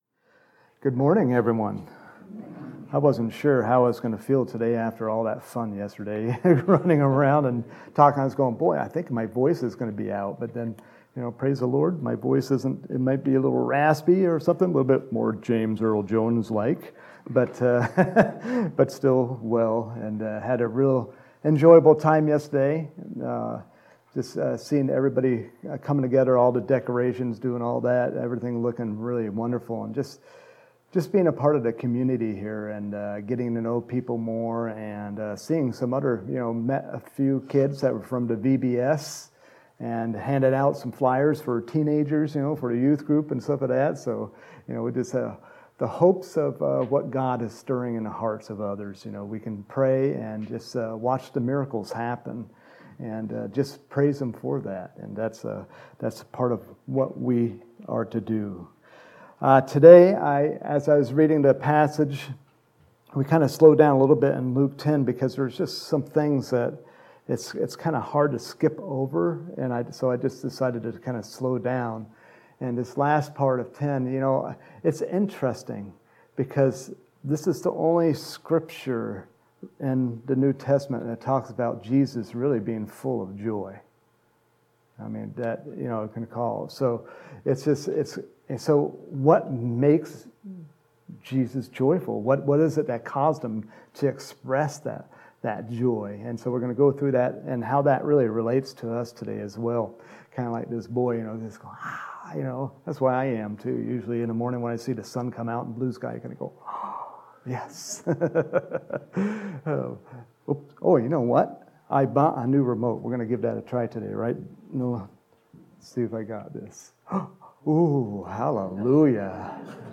Home › Sermons › September 19, 2021
Sunday Morning Sermon